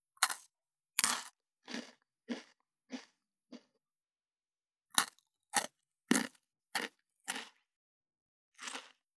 14.スナック菓子・咀嚼音【無料効果音】
ASMR/ステレオ環境音各種配布中！！
ASMR